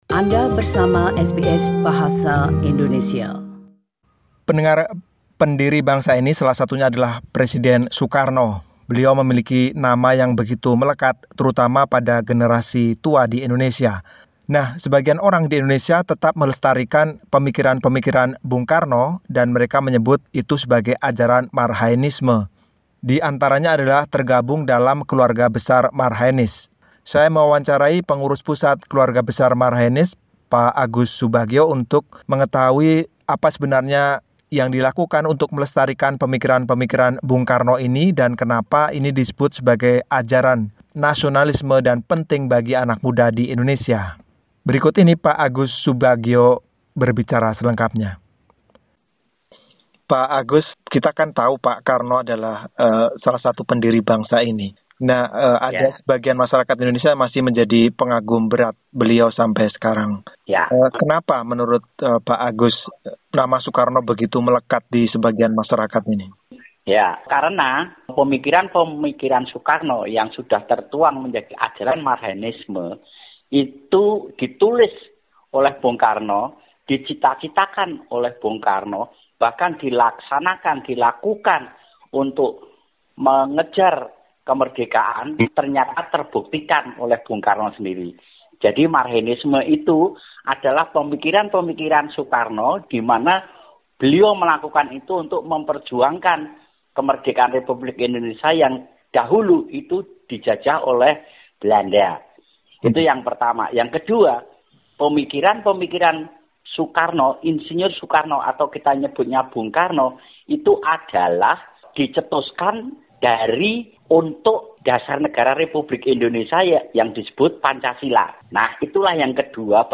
In the following interview